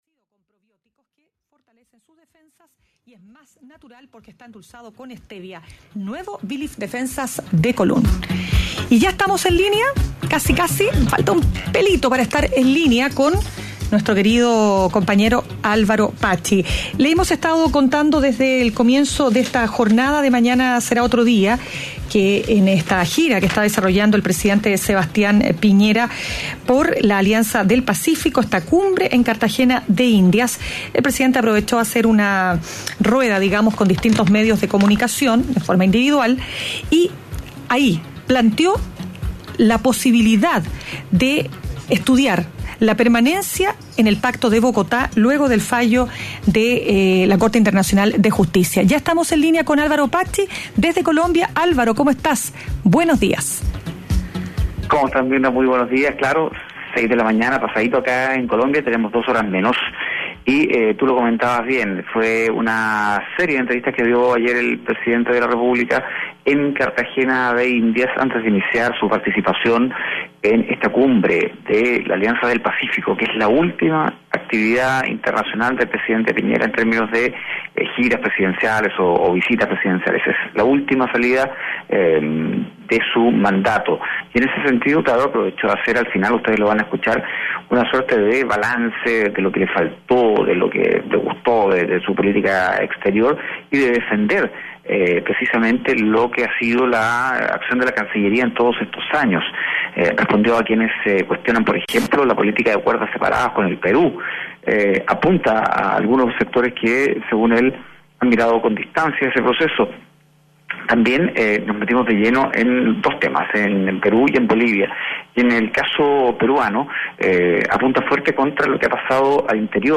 Entrevista-Sebastian-Pinera.mp3